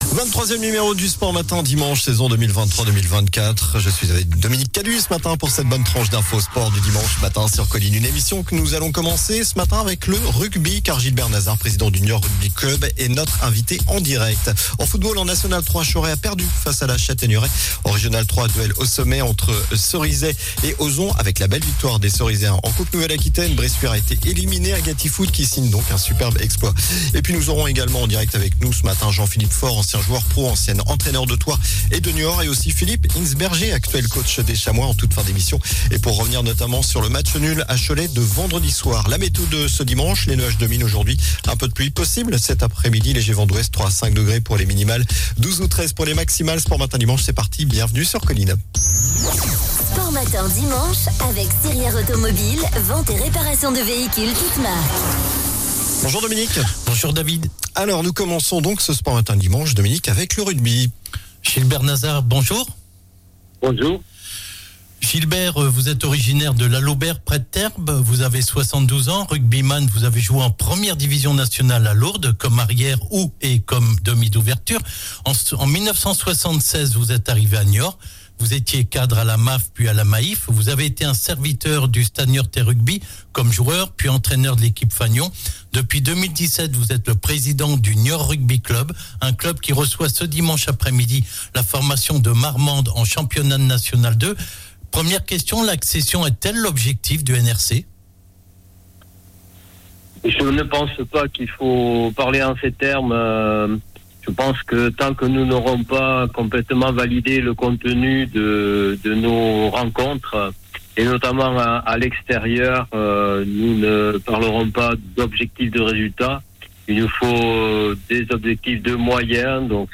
sport local